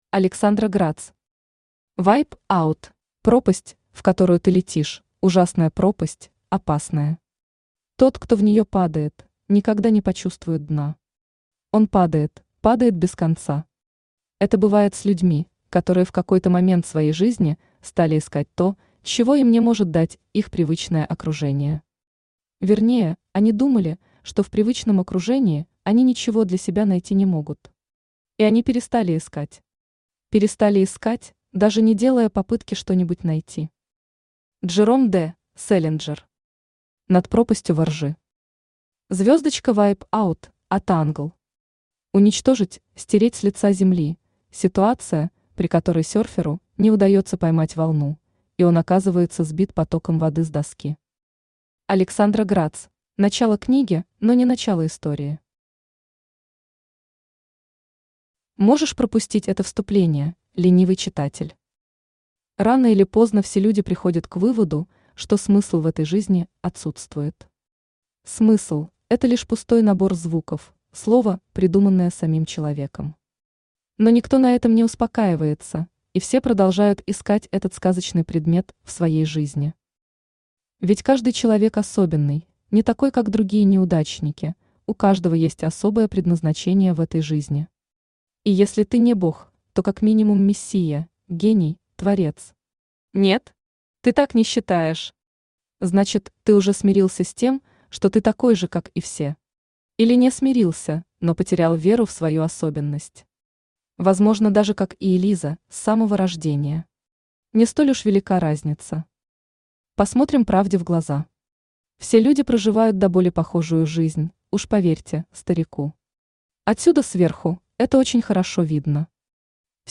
Аудиокнига Вайп-аут | Библиотека аудиокниг
Aудиокнига Вайп-аут Автор Александра Грац Читает аудиокнигу Авточтец ЛитРес.